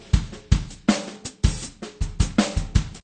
rhythm_3.ogg